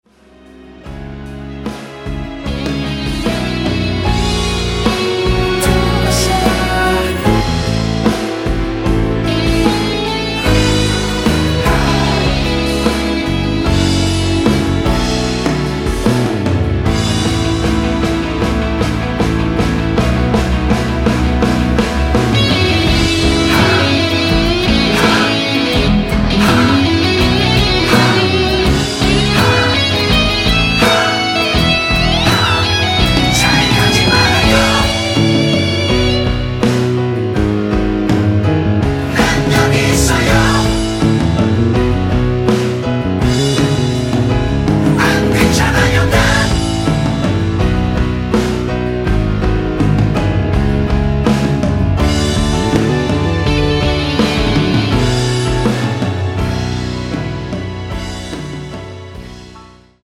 코러스 포함된 MR
앞부분30초, 뒷부분30초씩 편집해서 올려 드리고 있습니다.
중간에 음이 끈어지고 다시 나오는 이유는